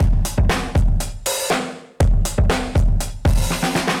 Index of /musicradar/dusty-funk-samples/Beats/120bpm/Alt Sound
DF_BeatB[dustier]_120-01.wav